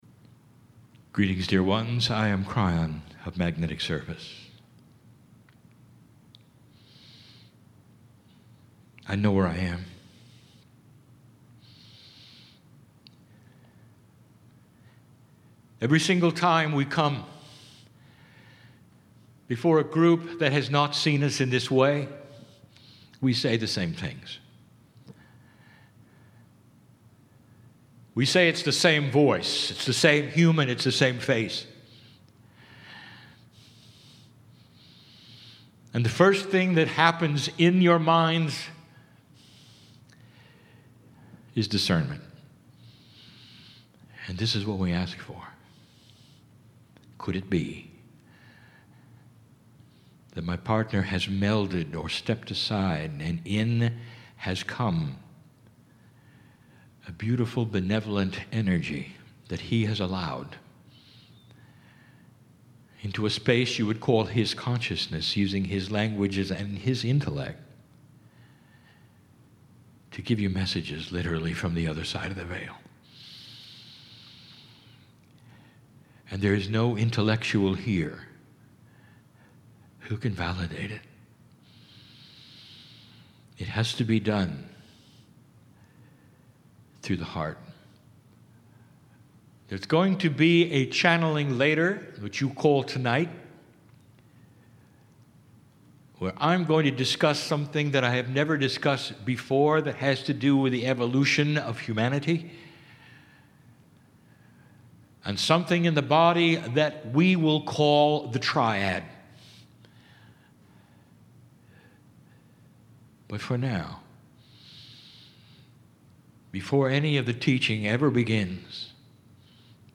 Charlottesville, Virginia
KRYON CHANNELLING
"Mini Channelling"